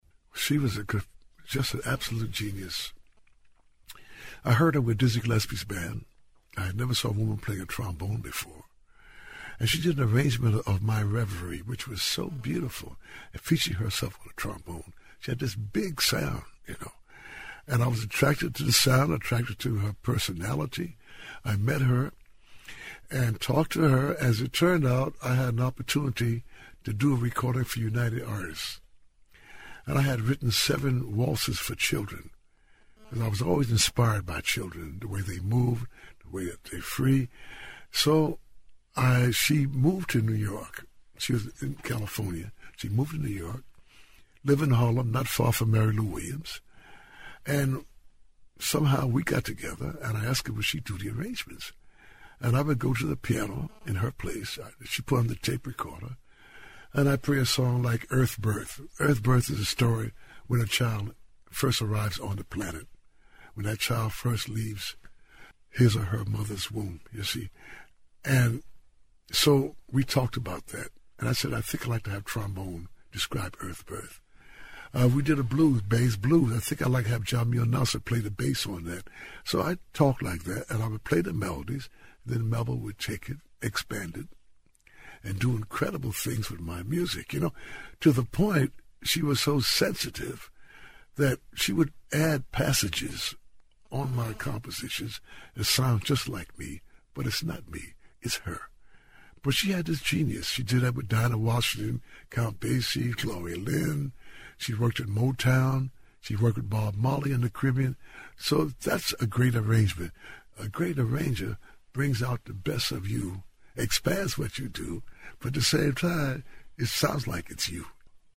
NEA Jazz Master Randy Weston discusses his long-time arranger, composer, trombonist, and an NEA master herself, Melba Liston. [1:58]